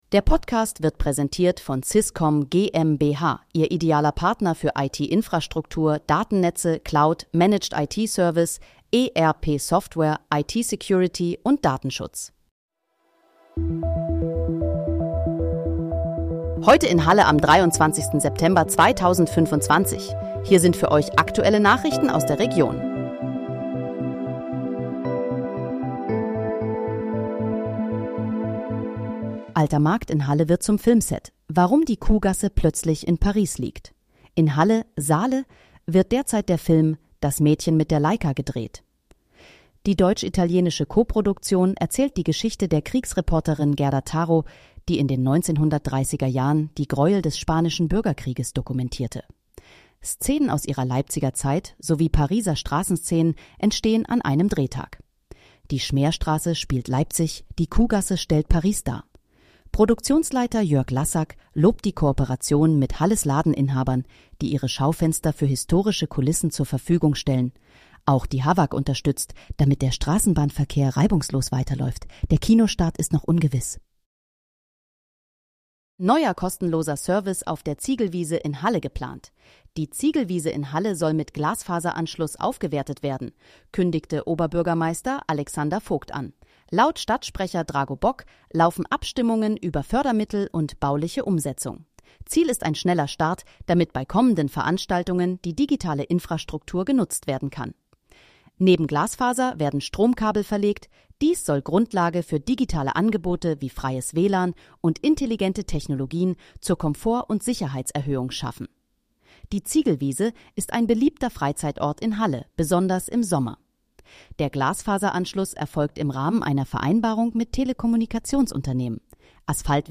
Heute in, Halle: Aktuelle Nachrichten vom 23.09.2025, erstellt mit KI-Unterstützung
Nachrichten